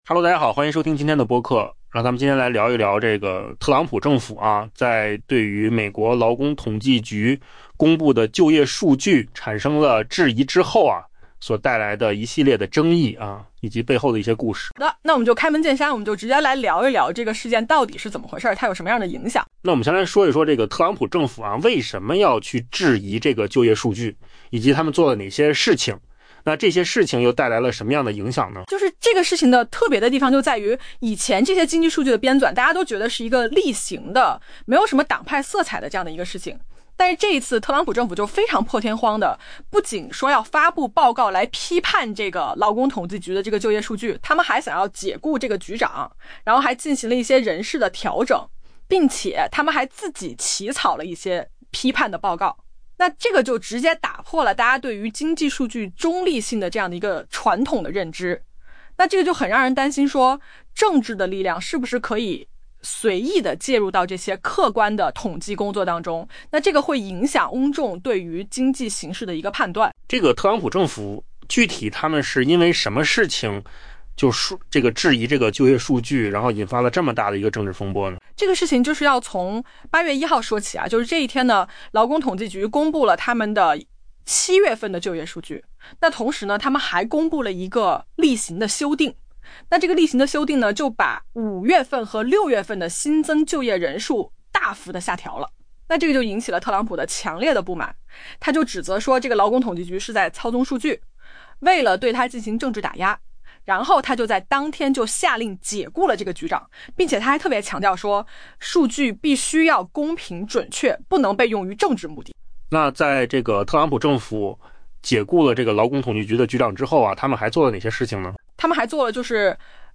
AI 播客：换个方式听新闻 下载 mp3 音频由扣子空间生成 《华尔街日报》 报道称，据知情人士透露，在特朗普解雇负责收集全国就业与价格数据的机构负责人五周后，其顾问团队正在起草一份报告，详述劳工统计局就业数据存在的所谓缺陷。